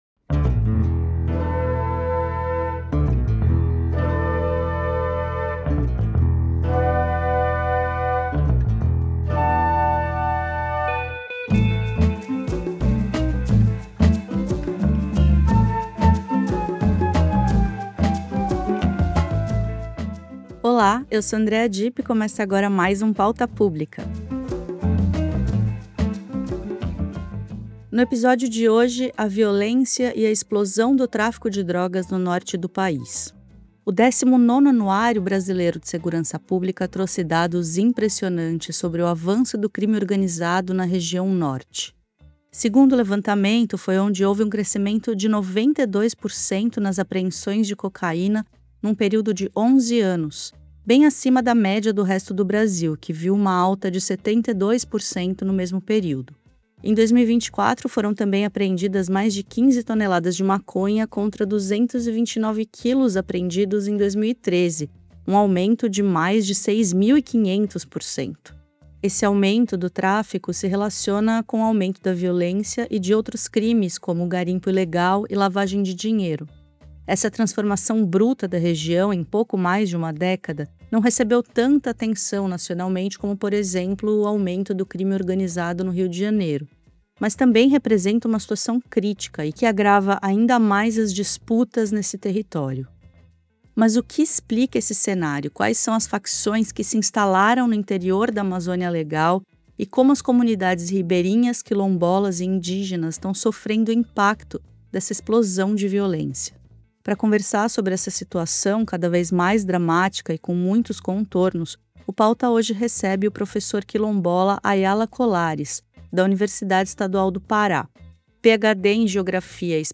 Especial: entrevista com a ministra Marina Silva